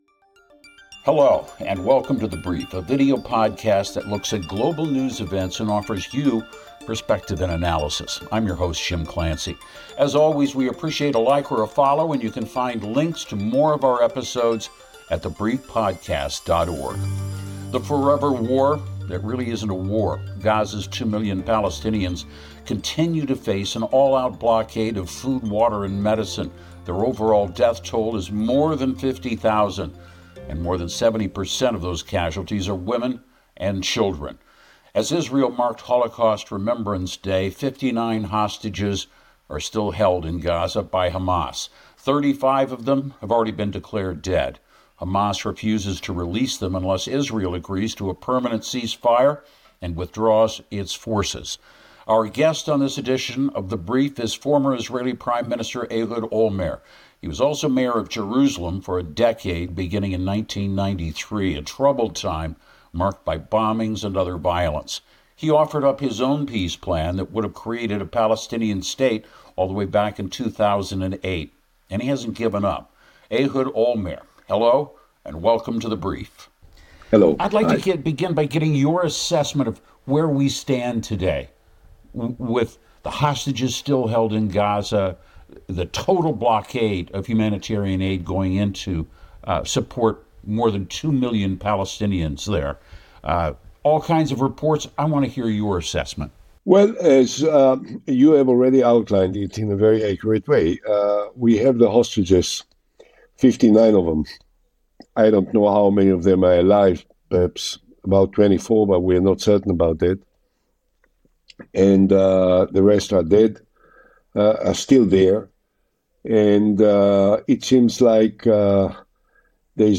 The conversation sheds light on Palestinian efforts to amplify their voices through storytelling and journalism amidst media bias and political challenges. The dialogue explores the need for Palestinian unity and questions the role of international powers in resolving long-standing issues.